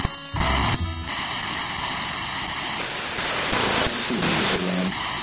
One night, I discovered a disembodied EVP that said “Just Leave Us Alone” on a recordable spirit box.
“Just Leave Us Alone” disembodied EVP caught on March 7, 2021
Just-Leave-Us-Alone-EVP-SBox-3.7.21.mp3